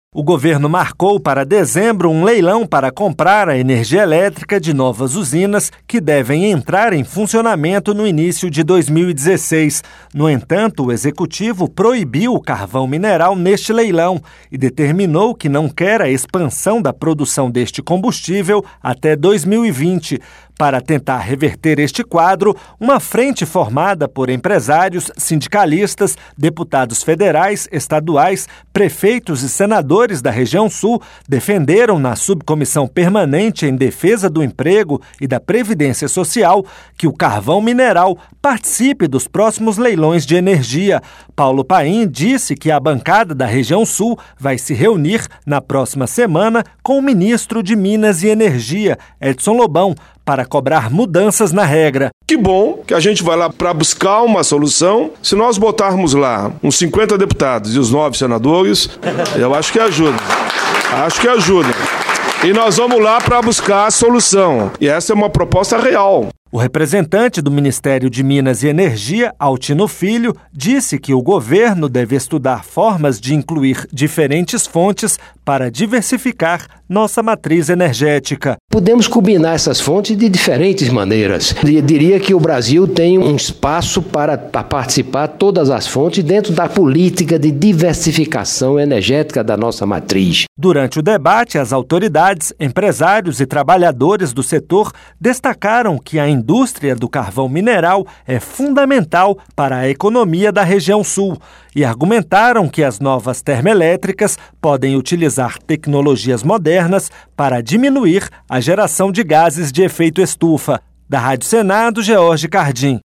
Empresários e sindicalistas do Sul querem incluir carvão mineral em leilões — Rádio Senado